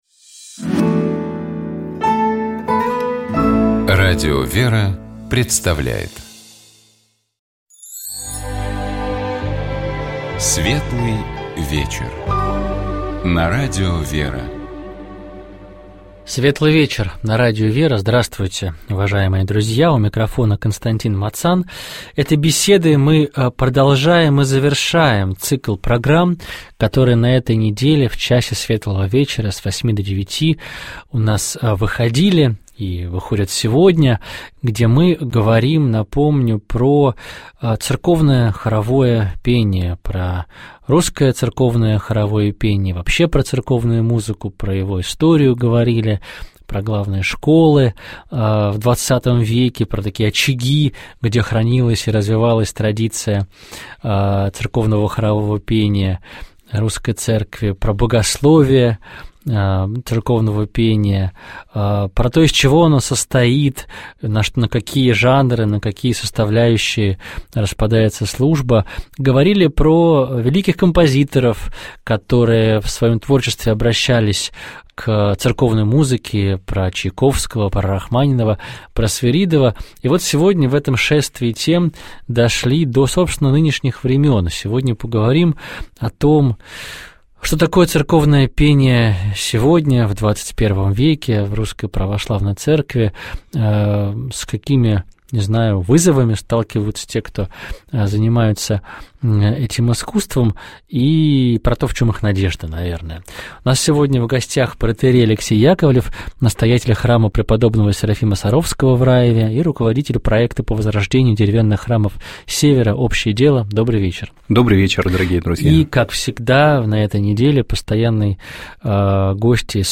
Комментарий эксперта: